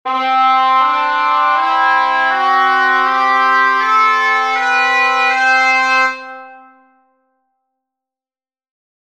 I think it sounds great, but it is noticeably different.
For the curious, I used Musescore2 to create these scale samples, due to it’s easy-to-apply tuning adjustments on notes, with the bagpipe sound specifically because it did not have vibrato.